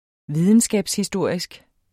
Udtale [ ˈviðənsgabs- ]